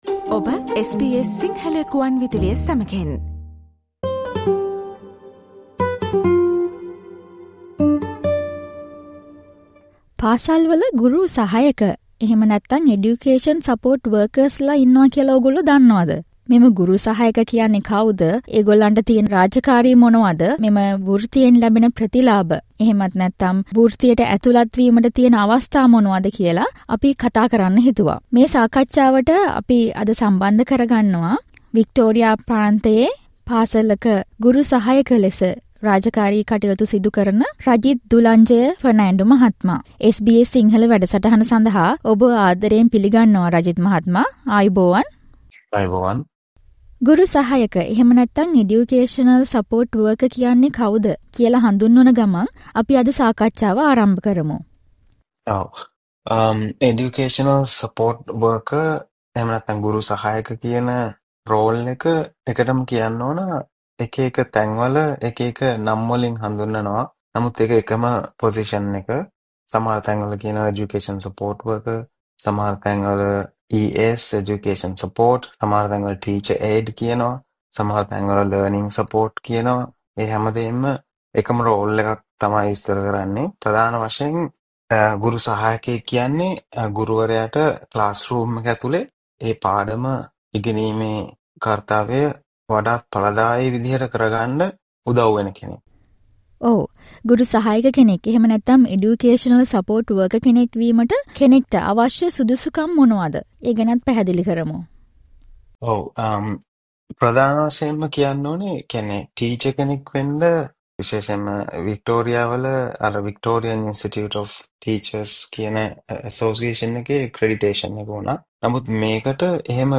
Here is more information about Educational Support Worker position. Listen to SBS Sinhala discussion.